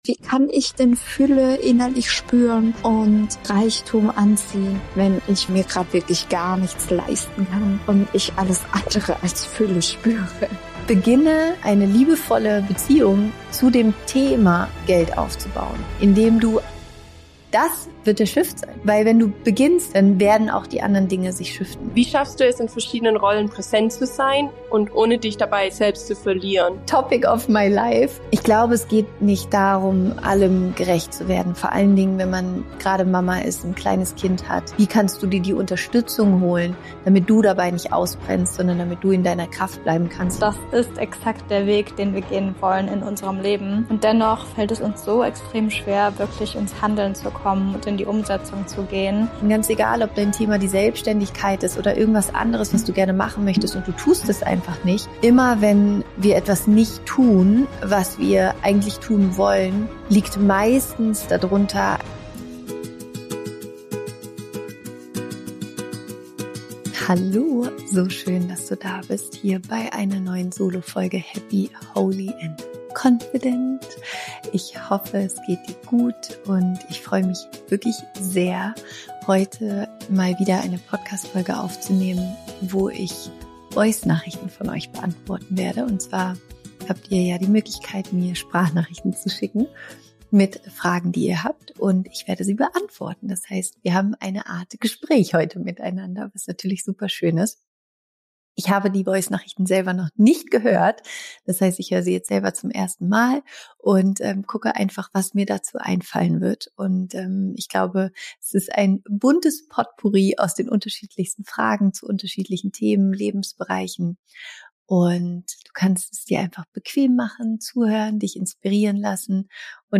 Ich beantworte eure Sprachnachrichten aus der Community – und teile Tipps, wie du deine Energie wieder dorthin lenkst, wo sie hingehört: zu dir, zu deinen Wünschen, zu deinem Leben.